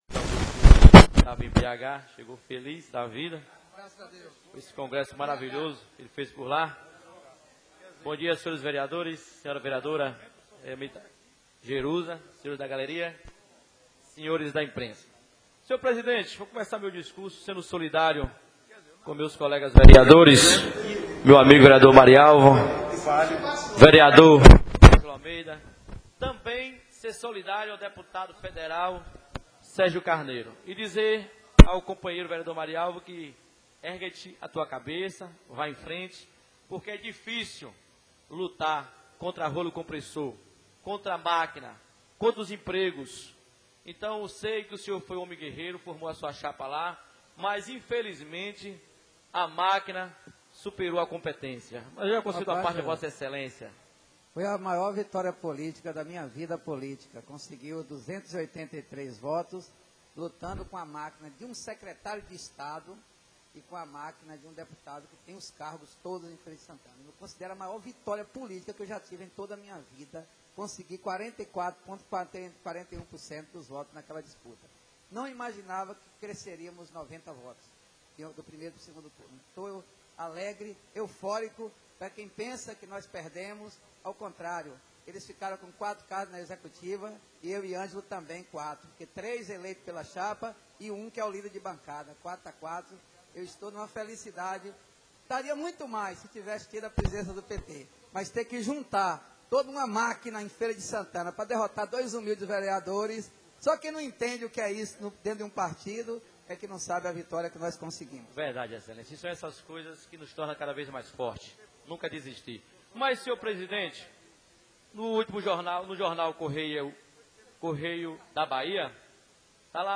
O vereador Sebastião Bastinho (PRTB) usou a tribuna para criticar o secretário estadual de saúde, Jorge Solla e o governador Wagner pela falta de vacina contra menigite.